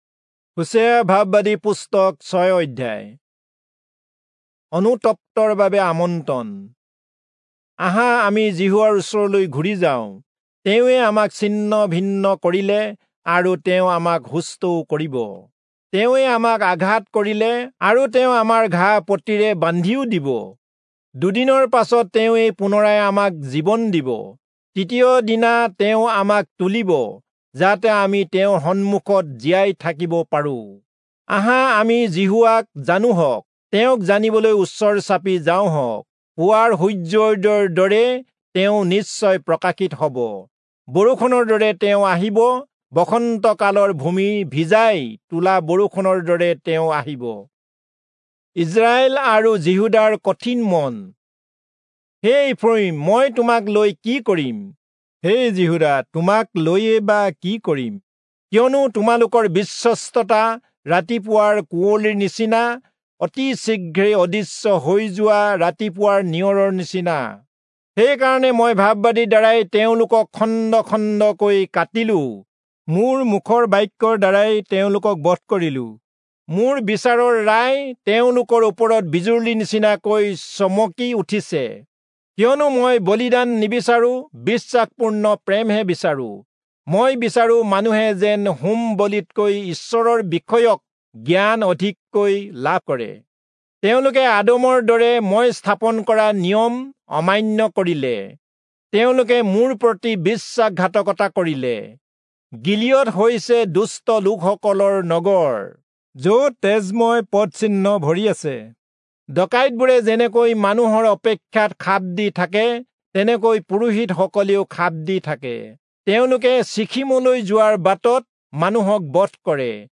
Assamese Audio Bible - Hosea 13 in Kjv bible version